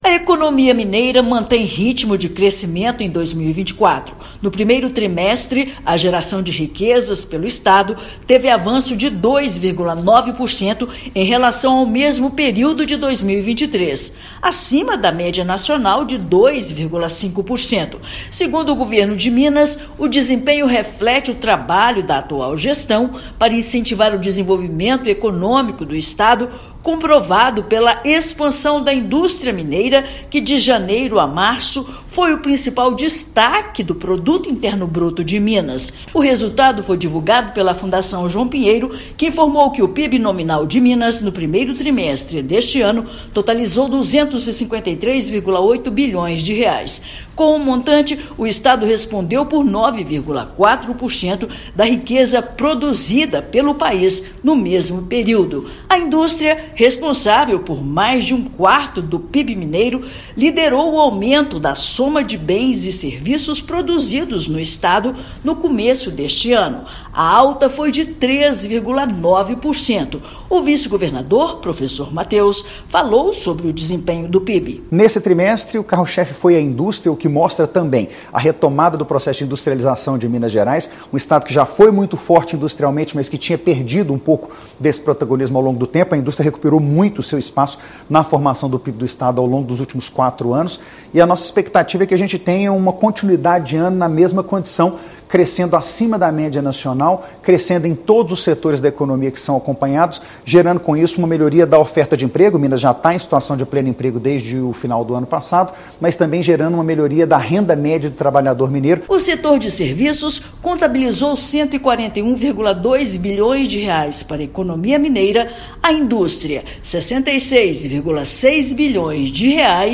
[RÁDIO] Minas Gerais mantém expansão em vários setores e economia cresce 2,9% no 1º trimestre de 2024
Resultado de levantamento divulgado nesta segunda-feira (1/7) pela Fundação João Pinheiro (FJP) conta com a contribuição de políticas públicas do Governo do Estado, como o projeto Sol de Minas, da Secretaria de Estado de Desenvolvimento Econômico (Sede-MG). Ouça matéria de rádio.